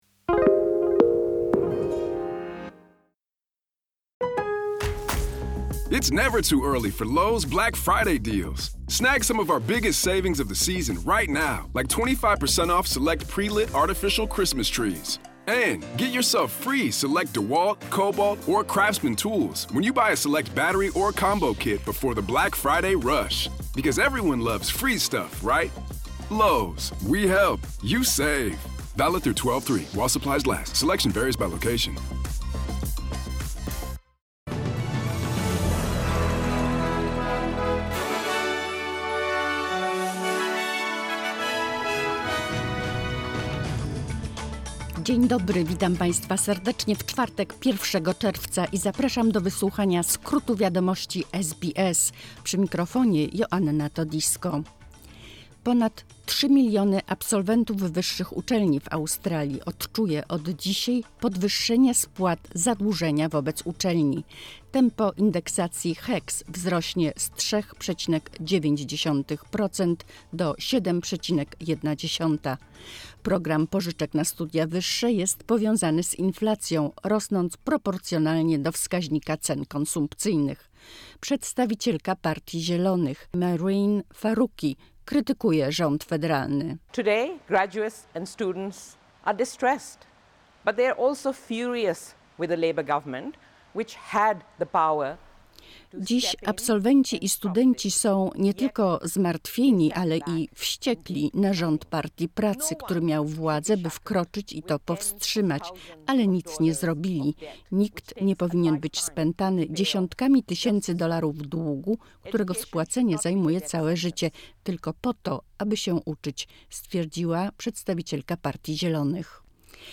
Wiadomości 1czerwca 2023 SBS News Flash